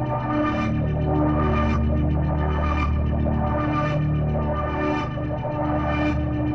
Index of /musicradar/dystopian-drone-samples/Tempo Loops/110bpm
DD_TempoDroneE_110-D.wav